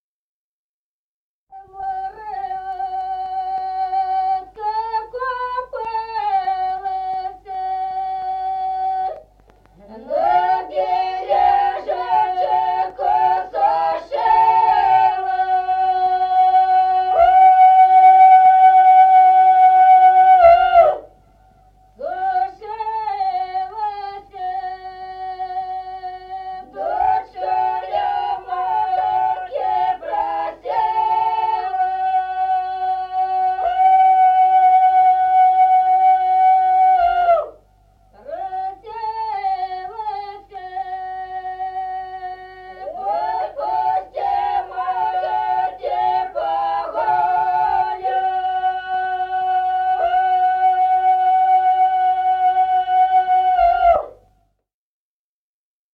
Песни села Остроглядово. На море утка (весновая).